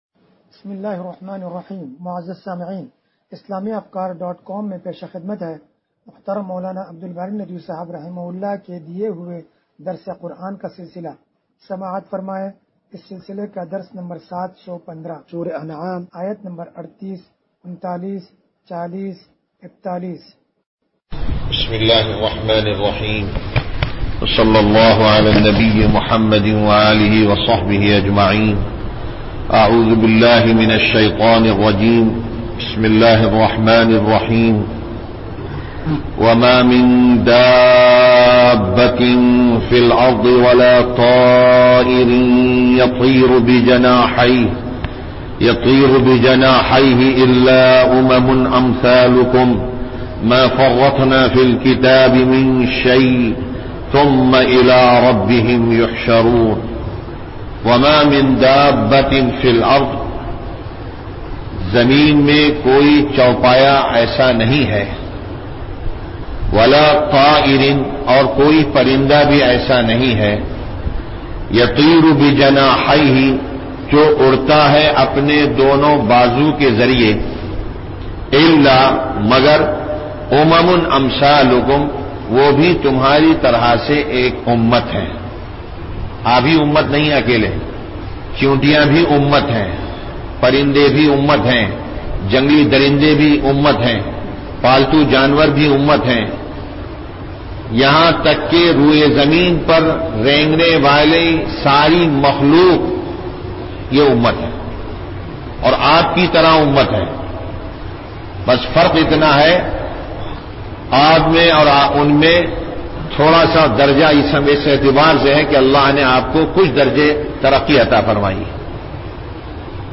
درس قرآن نمبر 0715